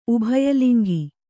♪ ubhaya liŋgi